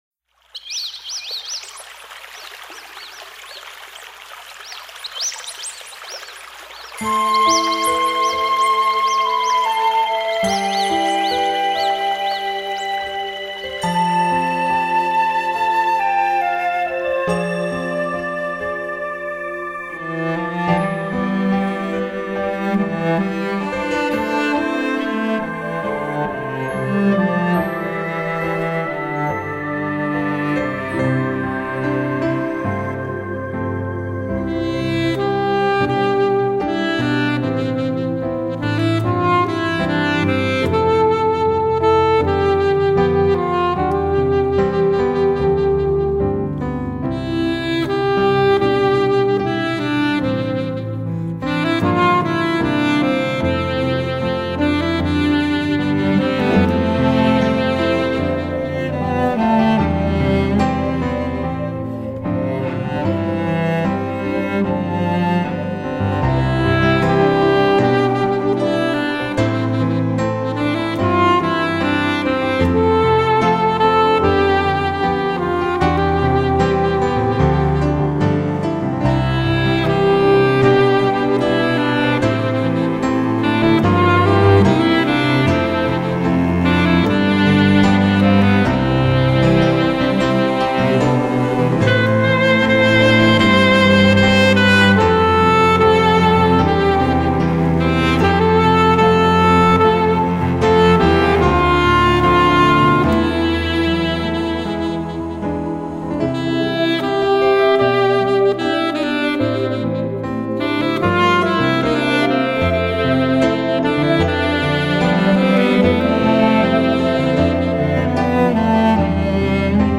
wp1921_2048* 소명찬양대